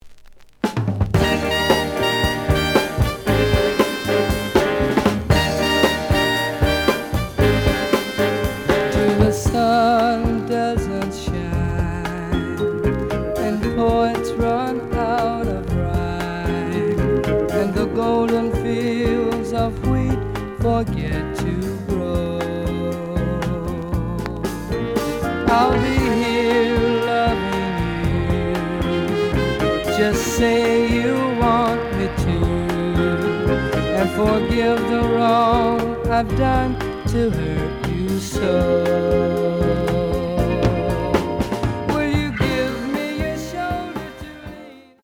The audio sample is recorded from the actual item.
●Genre: Soul, 60's Soul
B side plays good.)